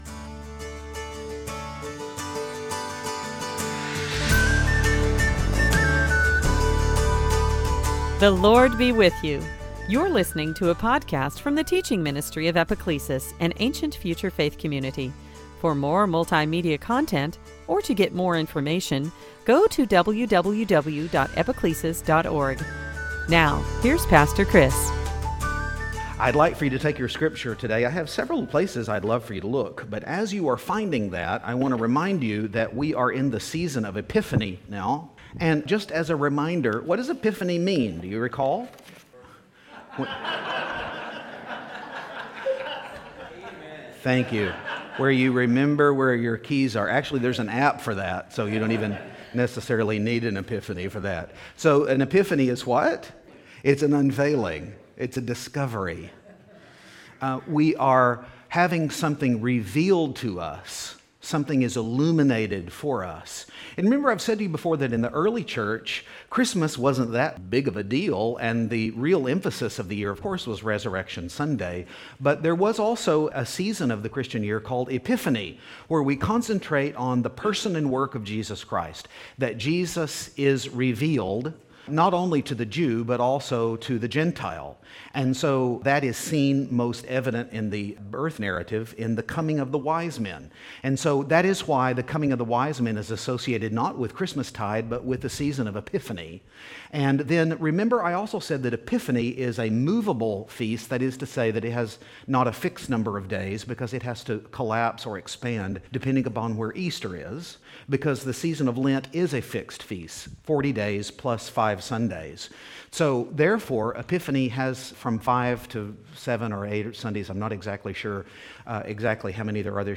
Sunday Teaching Service Type